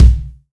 INSKICK12 -R.wav